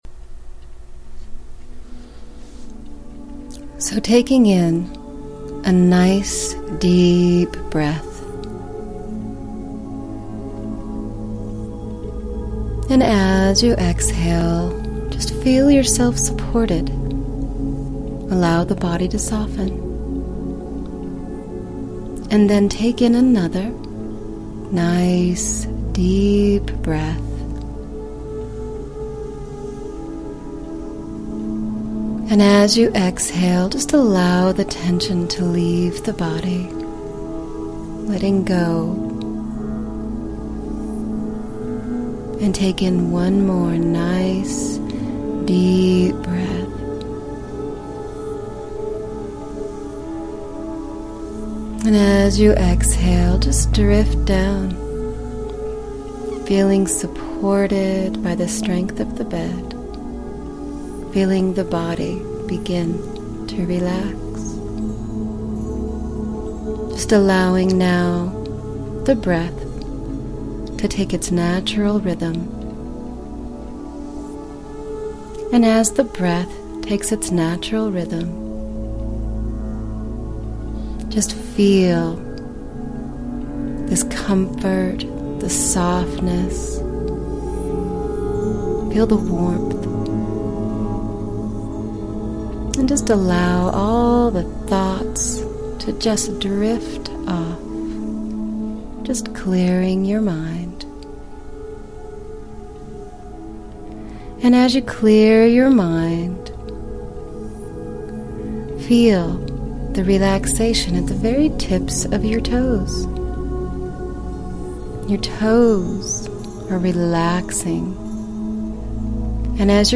Guided Meditation for better sleep.